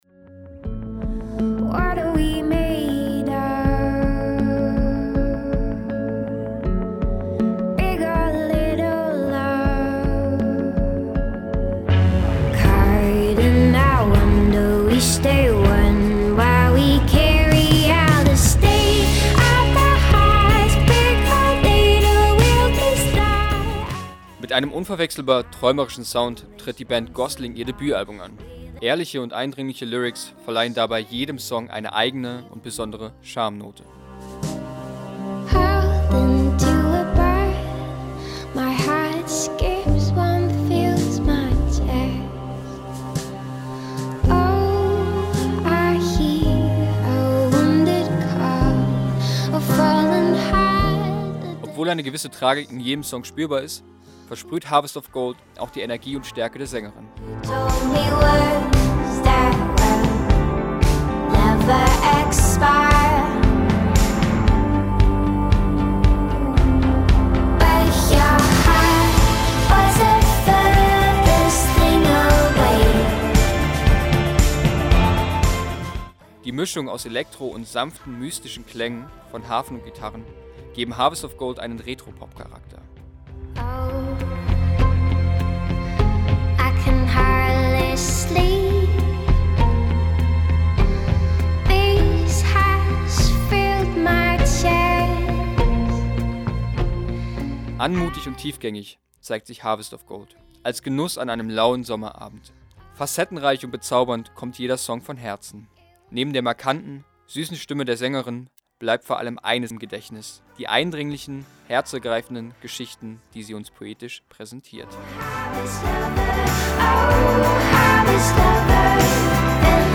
Die süße Australierin ist zurück mit einer träumerischen, sentimentalen Platte, die uns den Herbst schon jetzt schmackhaft macht. Ihre zarte Stimme und die sanfte Mischung aus Pop und Folk machen dieses Album einmalig.